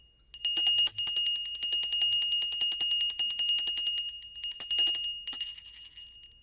bell call ding metal sound effect free sound royalty free Sound Effects